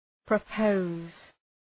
Προφορά
{prə’pəʋz}